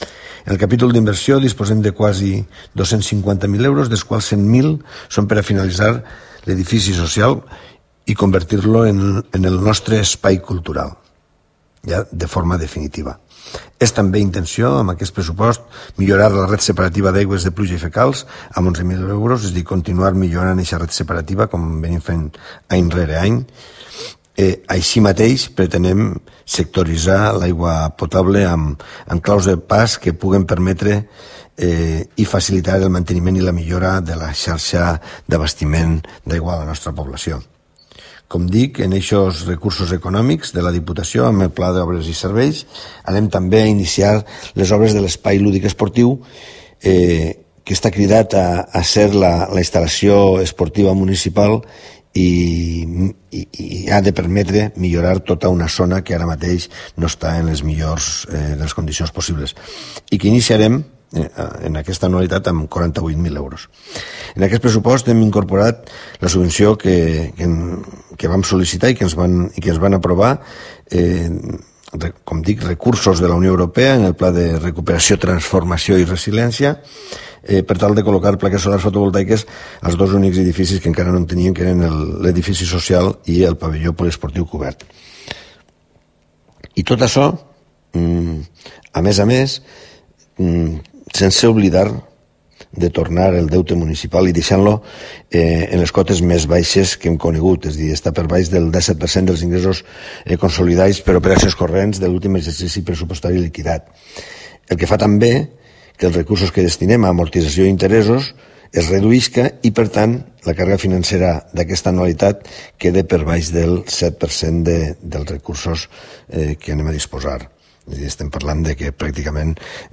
Sergio Bou, alcalde